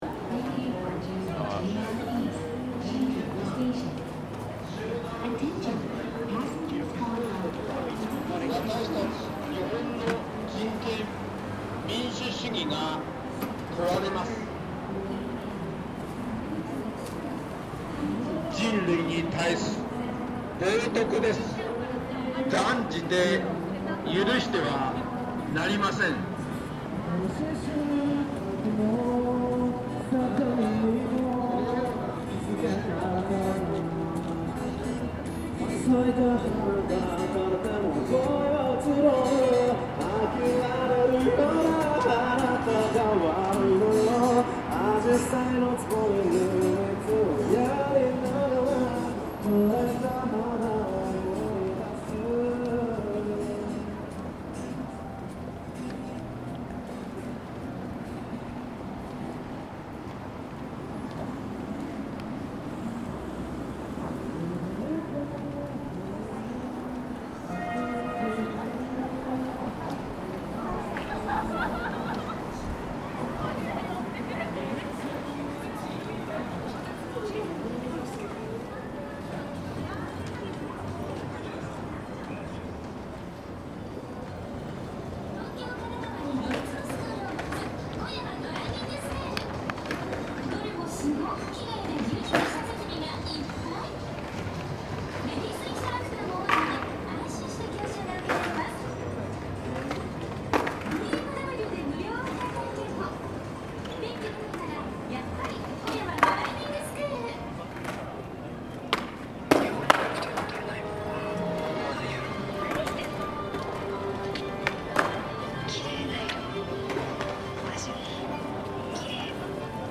Tokyo by night: sounds of Shinjuku